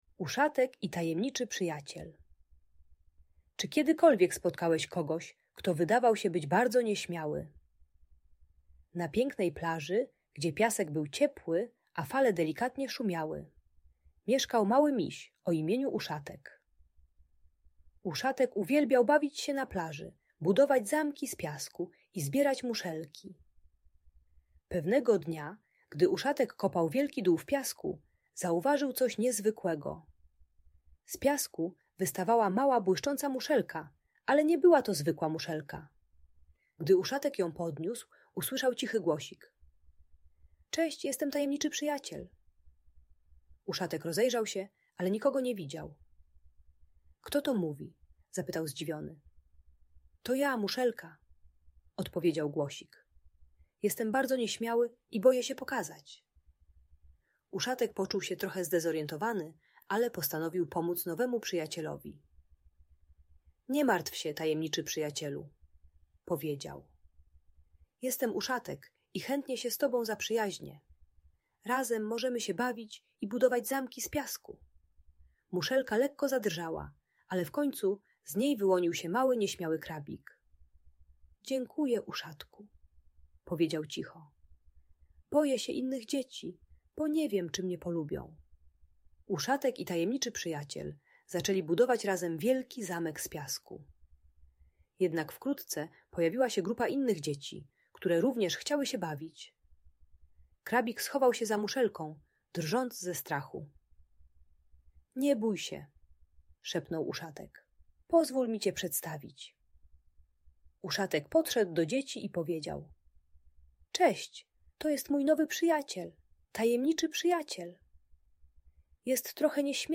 Uszatek i Tajemniczy Przyjaciel - Audiobajka